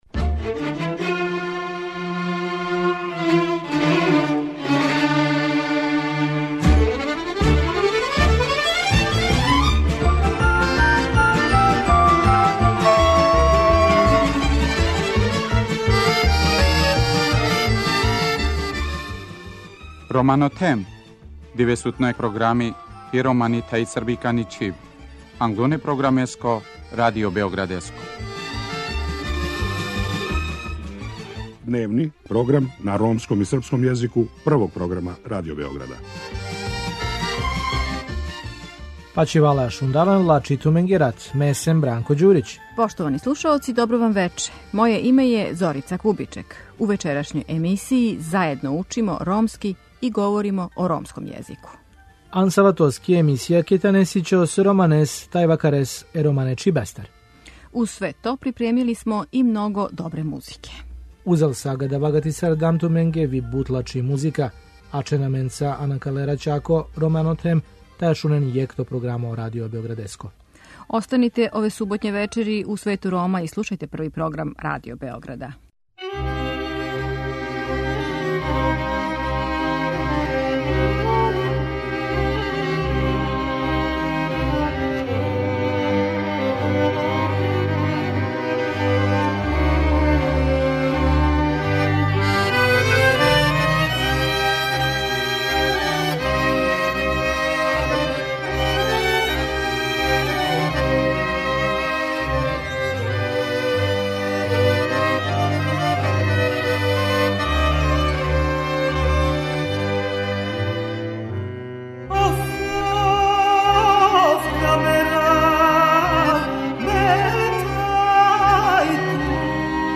У суботњој емисији уз доста добре ромске музике, заједно учимо ромски и говоримо о ромском језику.